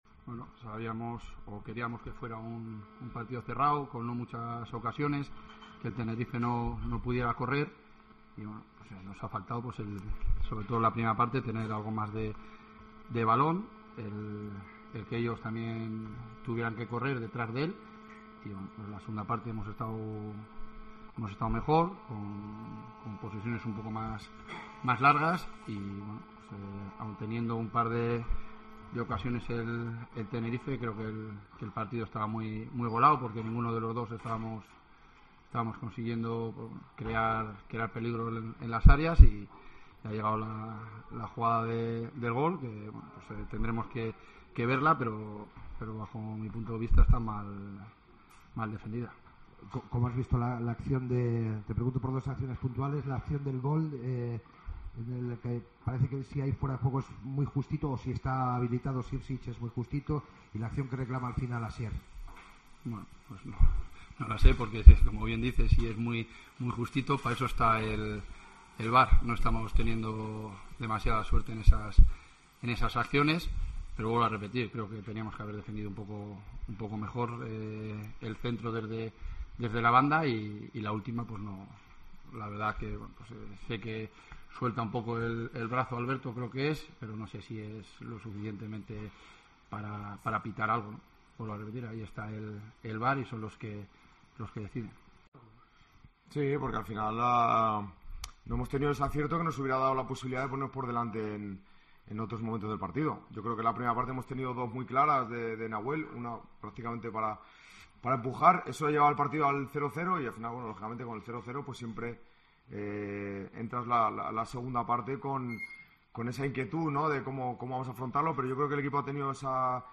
AUDIO: Escucha aquí el postpartido con las palabras de Jon Pérez Bolo y Rubén Baraja